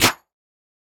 Snare 002.wav